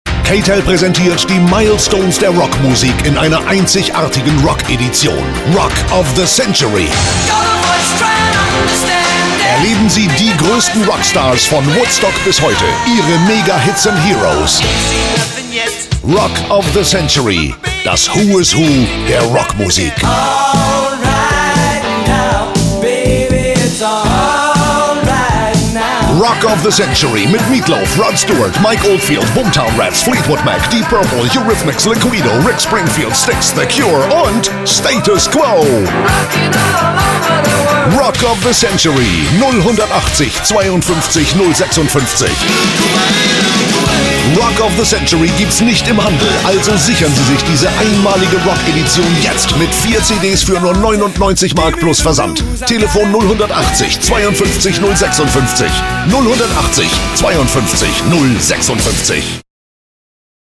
Kein Dialekt
Sprechprobe: eLearning (Muttersprache):
german voice over artist